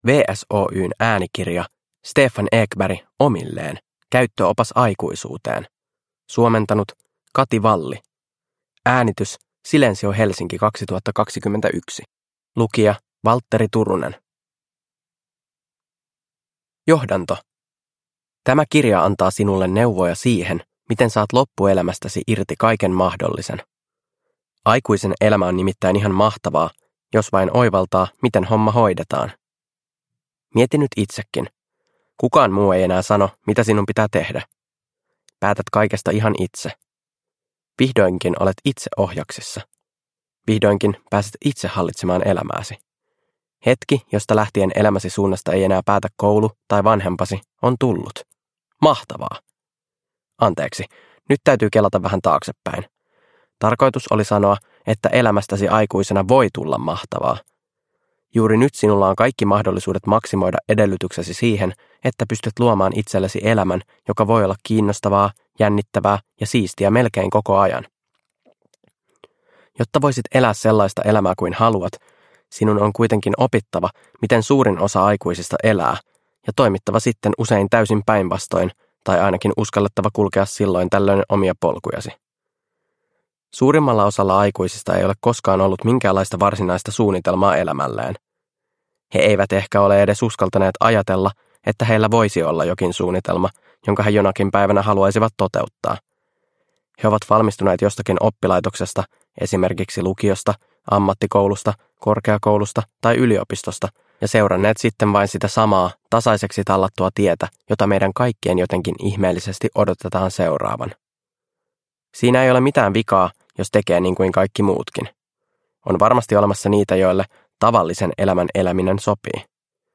Omilleen – Ljudbok – Laddas ner